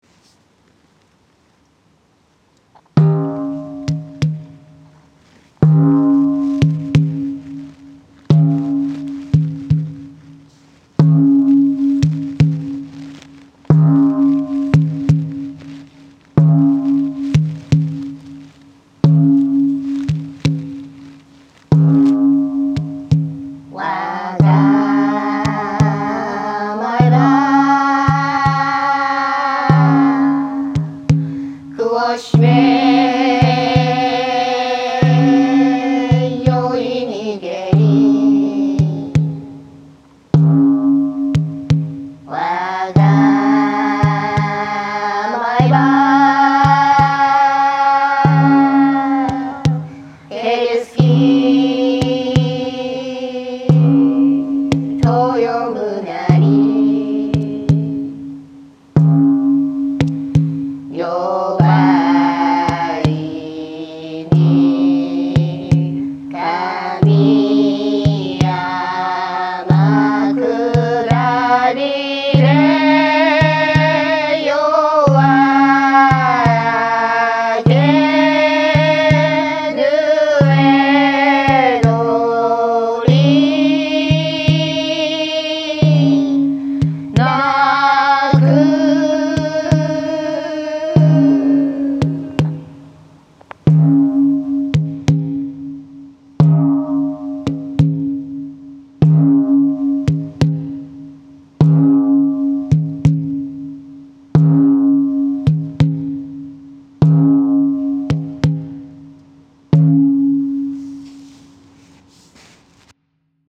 新买了萨满鼓，喜欢。立刻马上豪华整合（手机录音）了一段。
翻唱